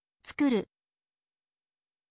tsukuru